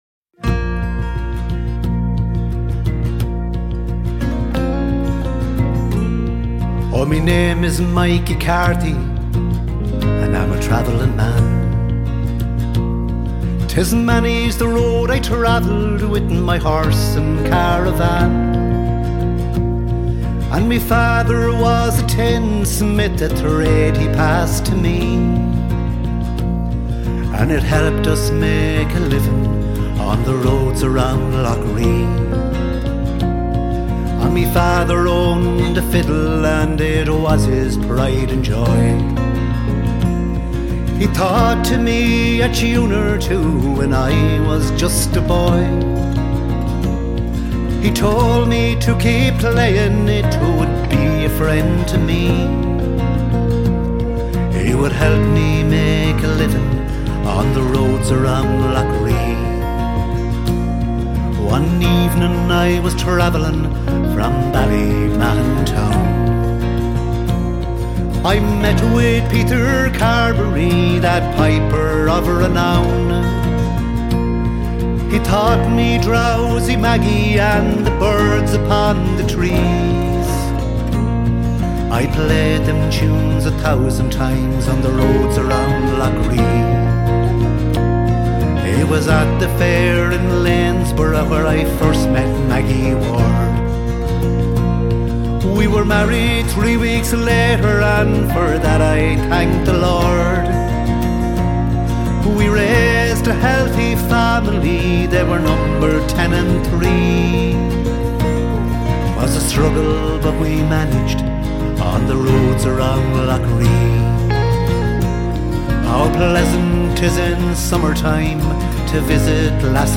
Guitar, Bodhrán, Harmonica, Vocals
Polished and beautifully performed